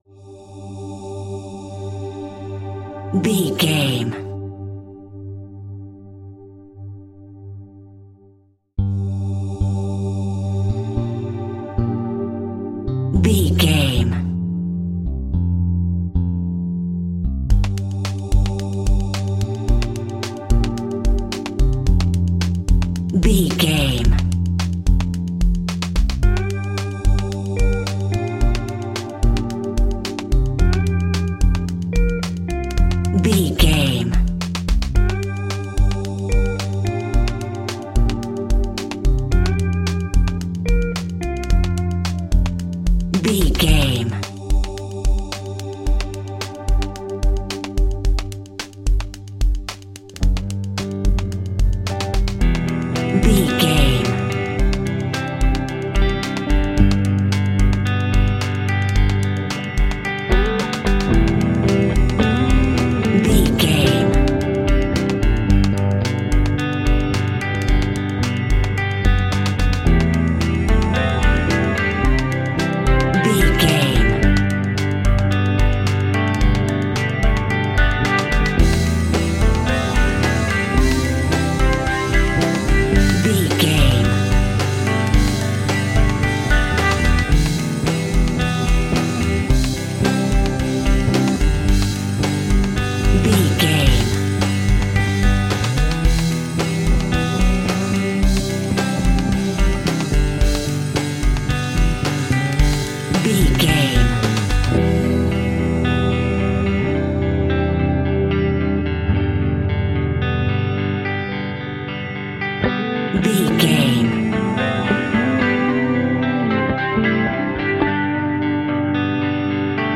Aeolian/Minor
electronic
new age
techno
trance
synths
Synth Pads
Synth Ambience